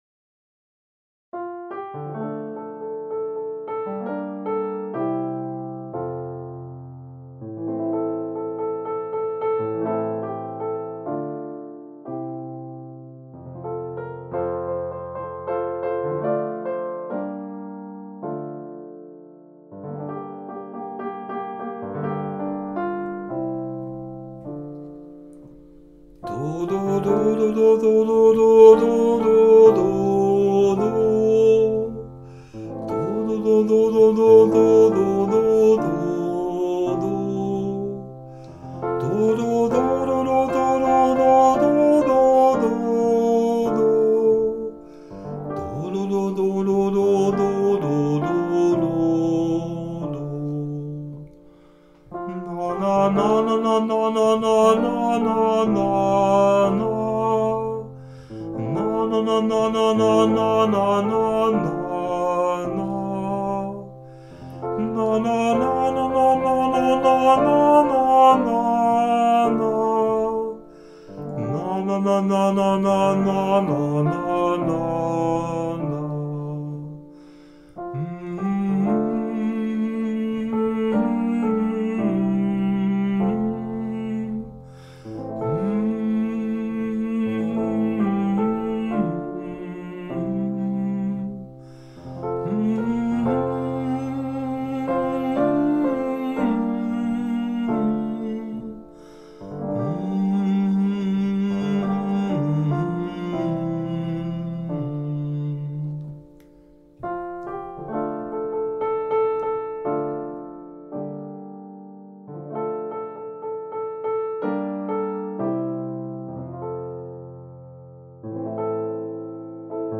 ohne Text, sondern nur mit Silben oder gesummt zu hören
Abschnitt 1: vierteilige Psalmodie, viermal gesungen bzw. gespielt
* E-Piano
* Summen mit mmmmh
* E-Piano mit Betonungen auch auf der letzten Note
psalmton-i-vierteilig-uebung.mp3